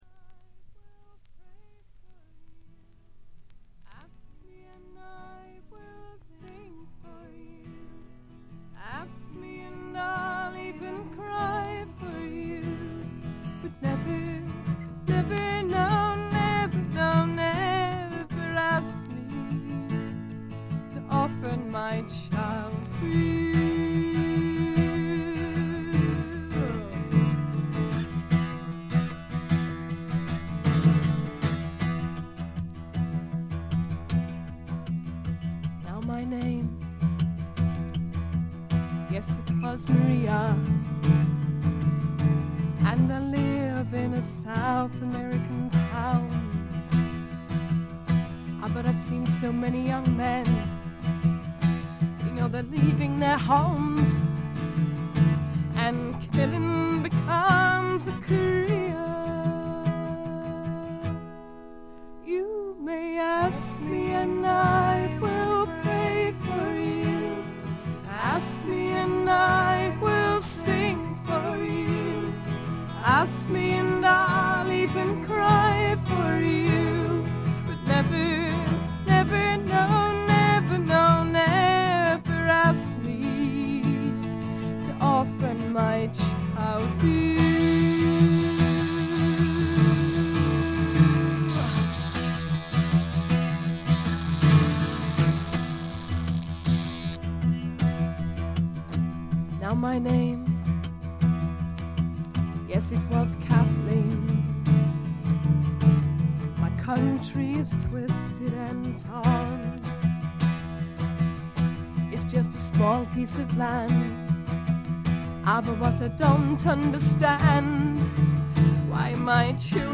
軽微なバックグラウンドノイズ。
試聴曲は現品からの取り込み音源です。
Vocals, Acoustic Guitar, Electric Guitar
Recorded At - Highbury Studios, London